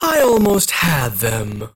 42 KB Viscous voice line (unfiltered) - I almost had them. 1
Viscous_near_miss_01_unfiltered.mp3